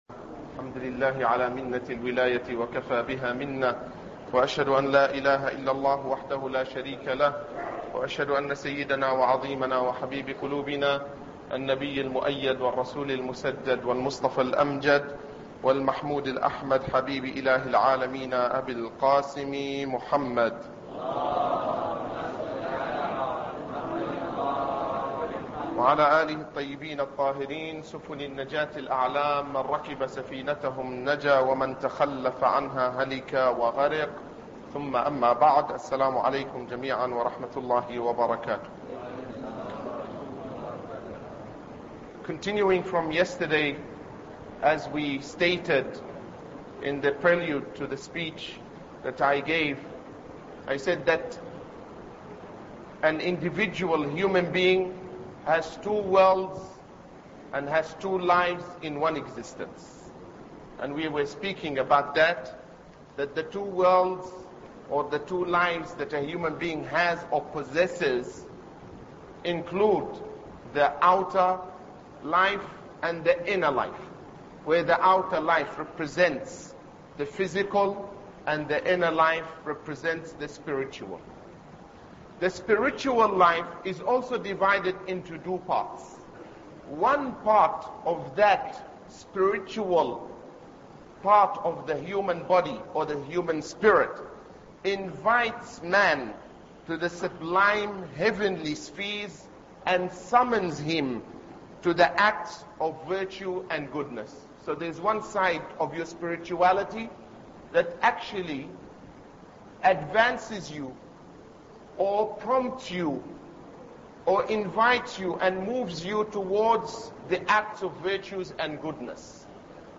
Ramadan Lecture 2